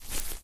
FootstepGrass05.ogg